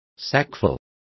Complete with pronunciation of the translation of sacksful.